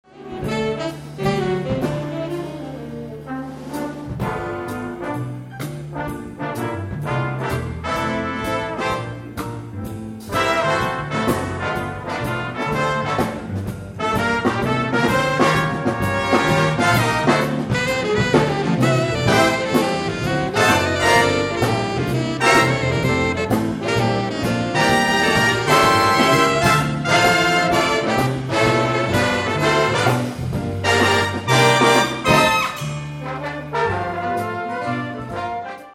a big band arrangement
with a featured clarinet soloist